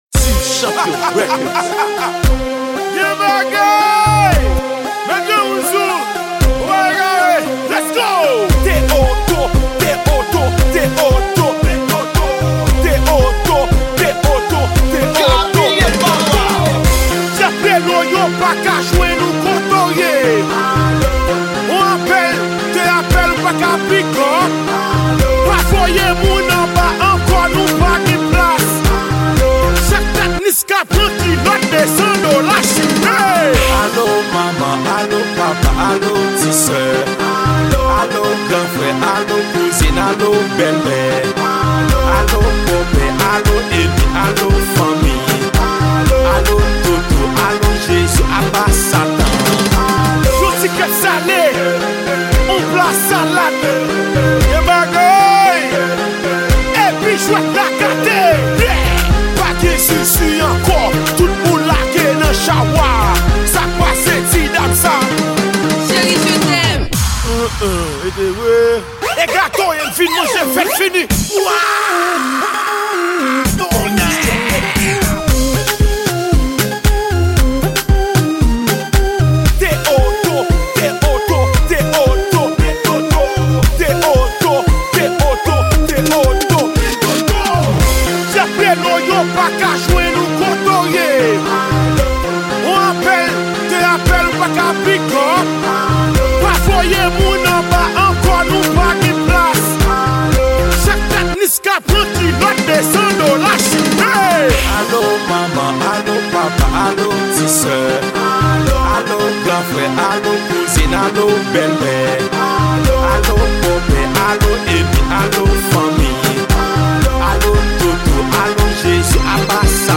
Genre : WORLD